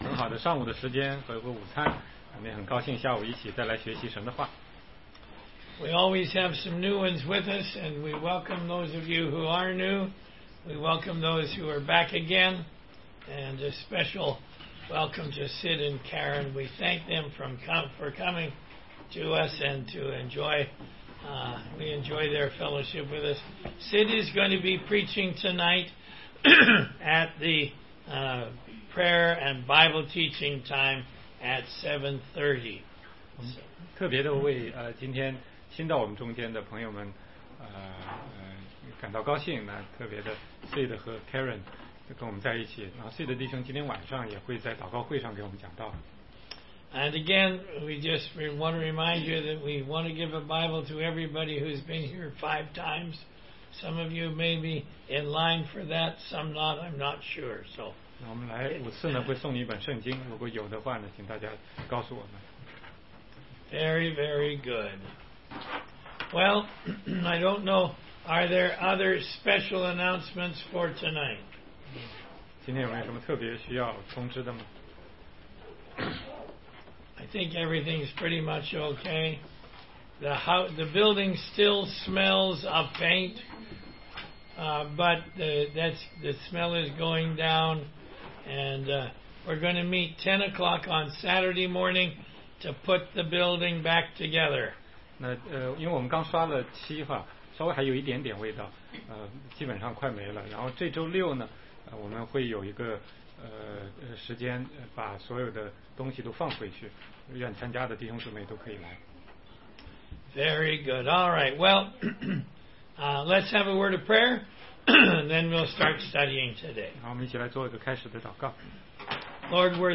16街讲道录音 - 罗马书1章16-25节